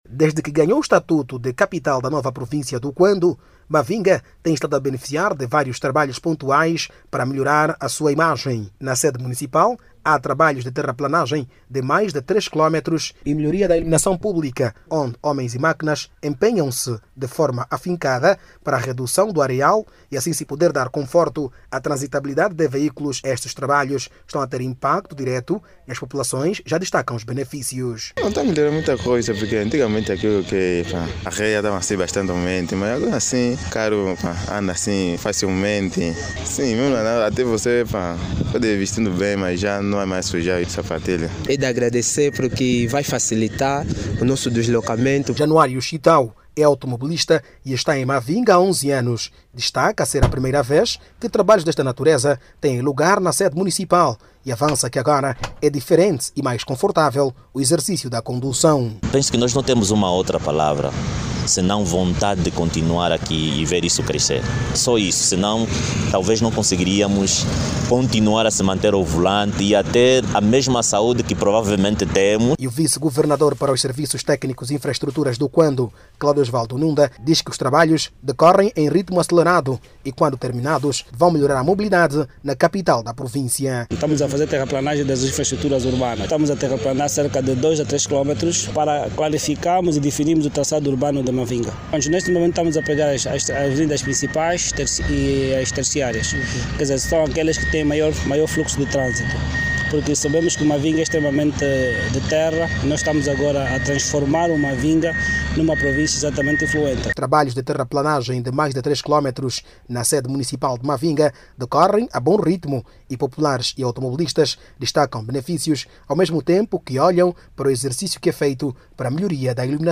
O governo da província do Cuando, esta a realizar trabalhos de terraplanagem nas principais infraestruturas, na sede capital Mavinga. Os trabalhos na recuperação de estradas e iluminação publicas, está a permitir uma melhor circulação de automobilistas e populares que aplaudem a iniciativa do governo. Clique no áudio abaixo e ouça a reportagem